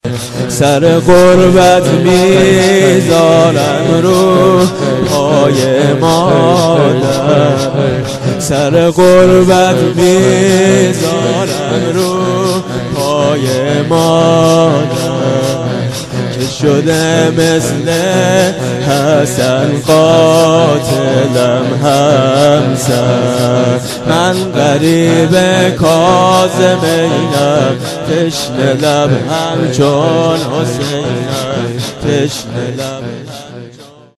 شور؛ سر غربت میذارن روی پای مادر
مداحی